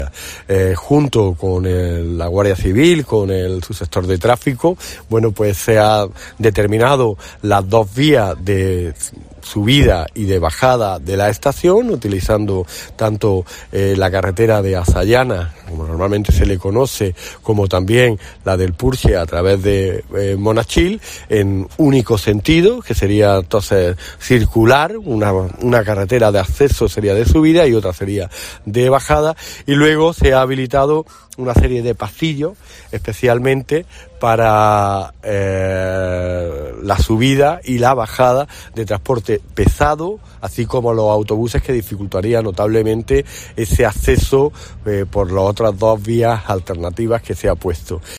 El delegado del Gobierno Antonio Granados